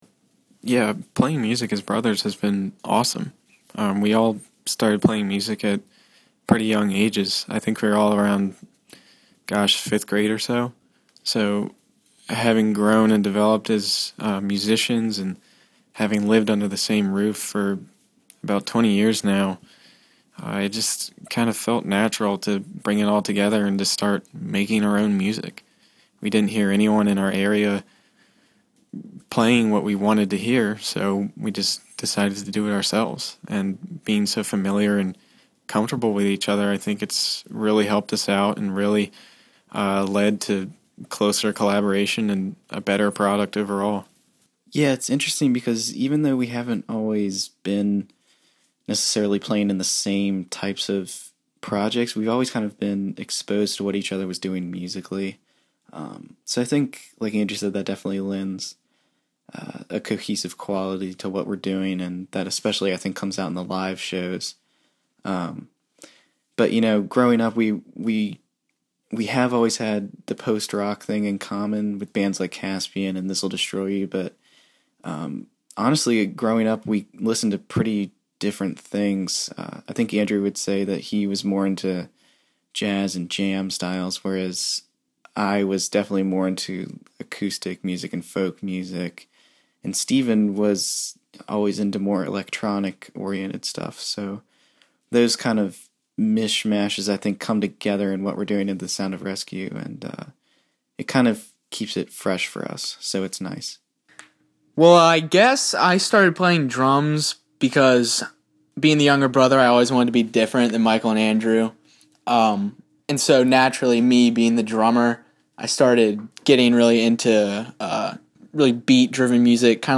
THE SOUND OF RESCUE INTERVIEW – August 2012